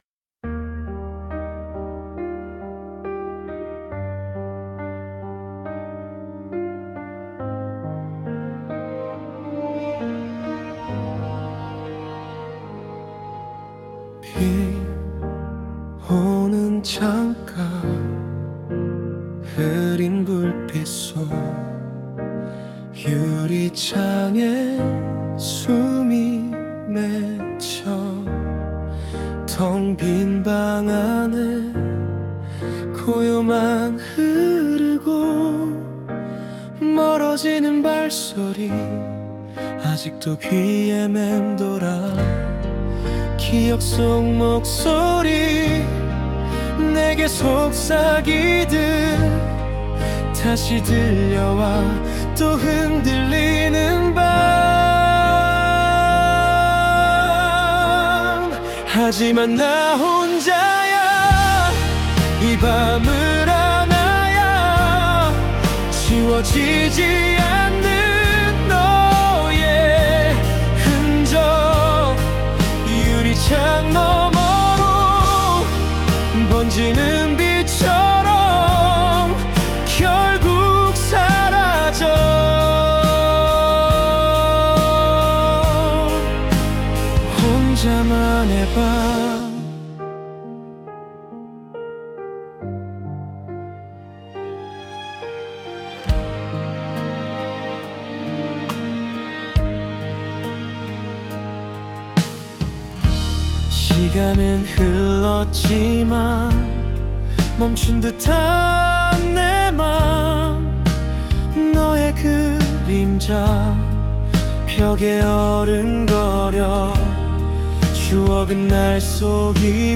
생성된 음악
다운로드 설정 정보 Scene (장면) Topic (주제) 비 오는 창가, 흐린 불빛, 유리창에 맺힌 숨, 멀어지는 발소리. 기억 속 목소리가 다시 들리는 듯하지만, 결국 나 혼자만의 밤을 받아들이는 장면 Suno 생성 가이드 (참고) Style of Music Female Vocals, Soft Voice Lyrics Structure [Meta] Language: Korean Topic: 비 오는 창가, 흐린 불빛, 유리창에 맺힌 숨, 멀어지는 발소리.